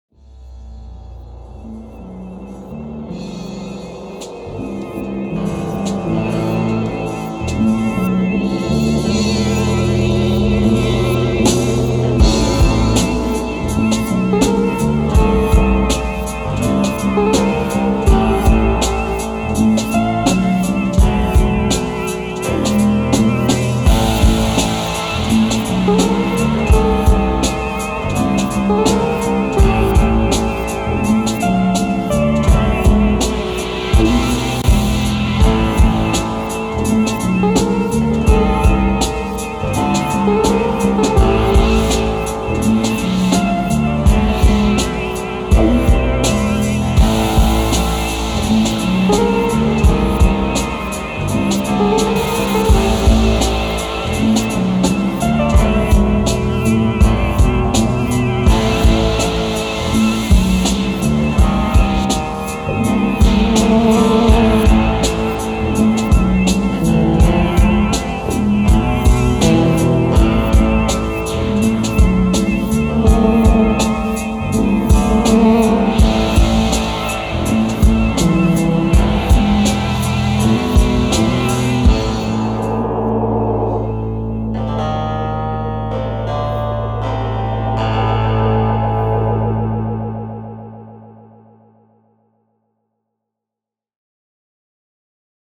Disorientated psychedelic groovy film noir mashup.